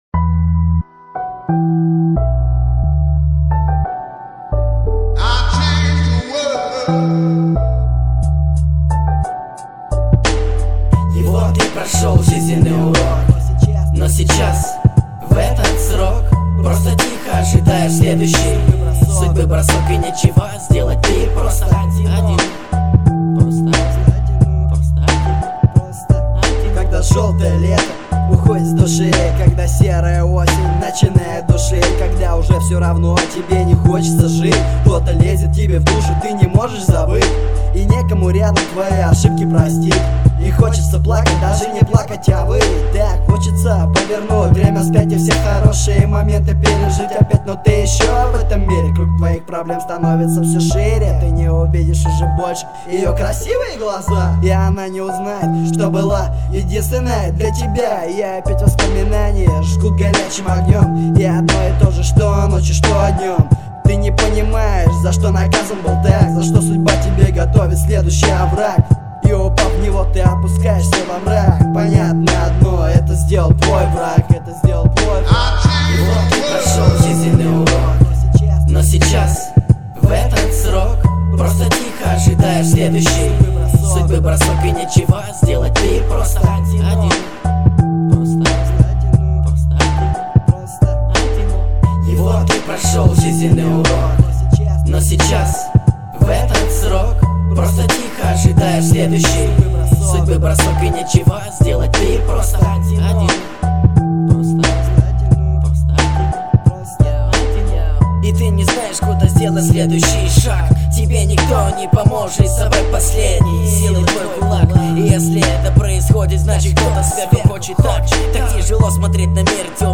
XXI век Хип-хоп Комментарии
mp3,5437k] Рэп